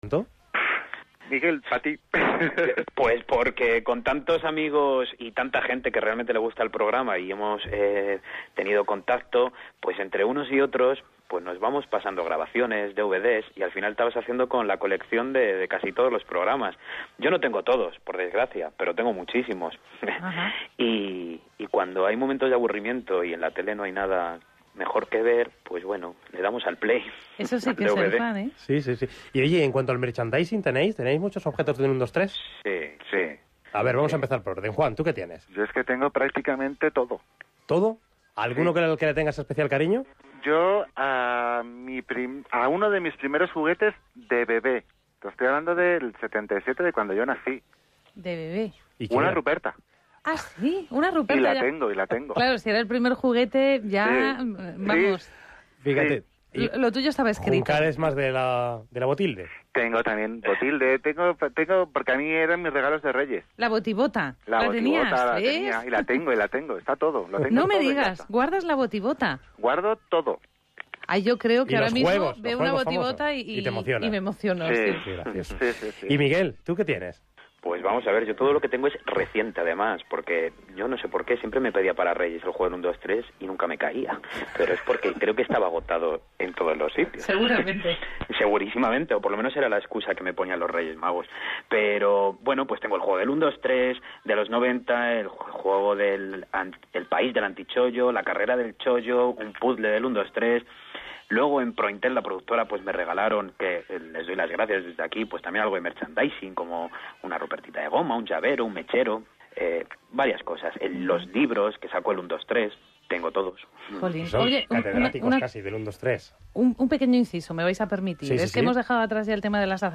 Tercera parte de la charla